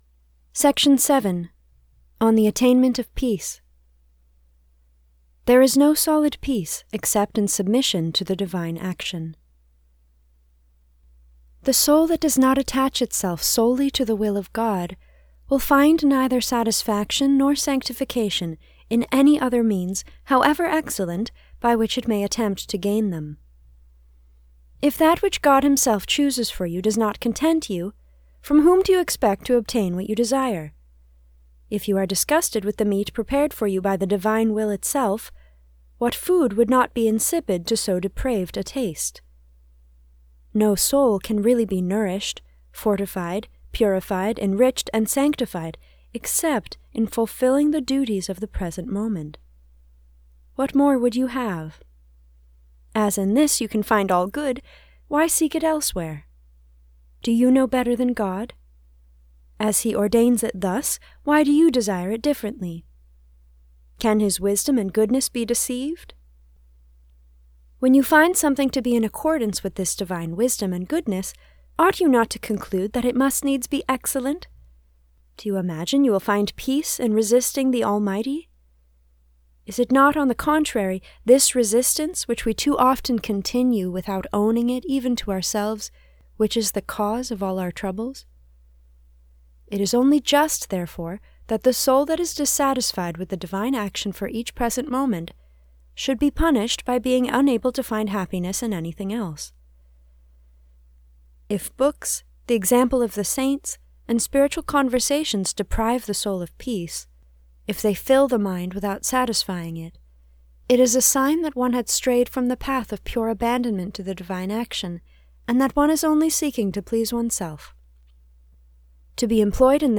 This is a reading from the spiritual classic Abandonment to Divine Providence by Jean Pierre de Caussade (1675 - 1751).